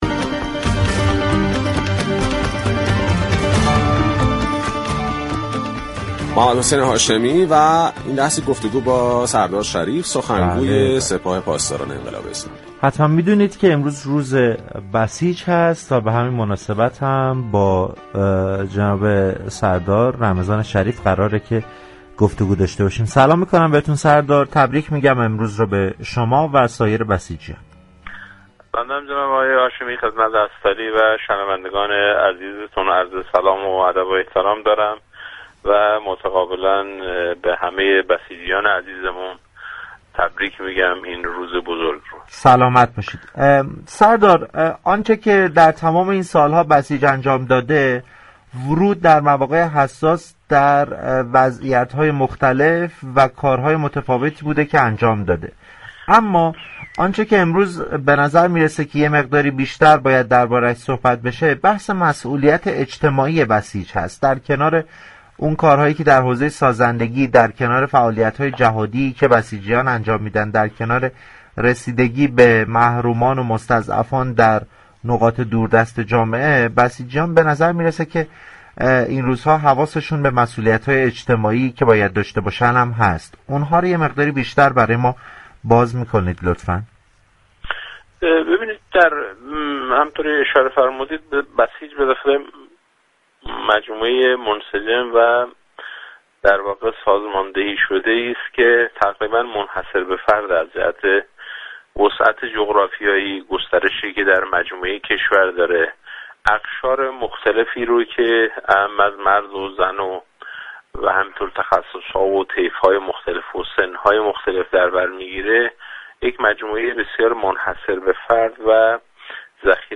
به گزارش پایگاه اطلاع رسانی رادیو تهران، سردار رمضان شریف به مناسبت روز بسیج ، در گفتگو با برنامه پارك شهر 5 آذرماه درباره مسئولیت های اجتماعی بسیجیان گفت: بسیج مجموعه ای منسجم و سازماندهی شده است كه می توان گفت به دلیل وسعت جغرافیایی، اقشار مختلف تشكیل دهنده آن اعم زن و مرد و تخصص های مختلف در طیف ها و سن های مختلف در تمامی سطح كشورمجموعه ای منحصر به فرد در مقابله با بحران ها و دفاع از میهن است.